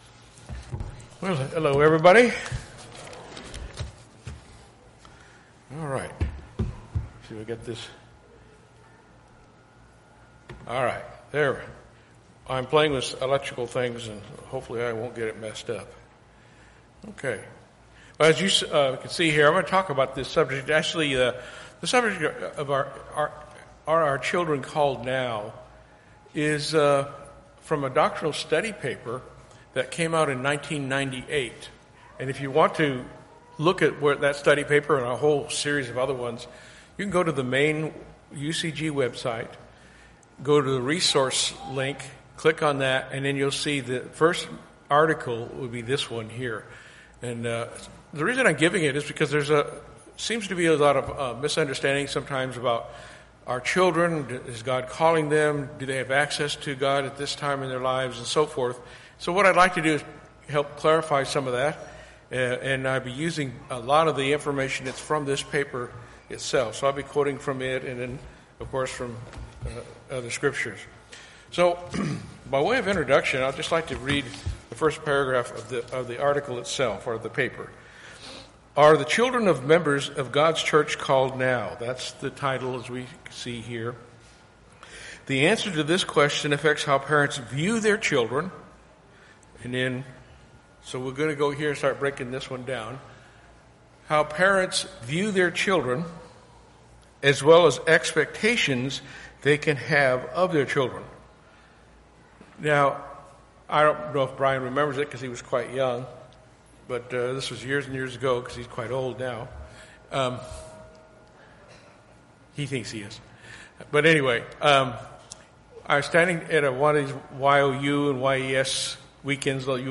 Sermons Are Our Children Called?
Given in Dallas, TX